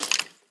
sounds / mob / skeleton / step3.mp3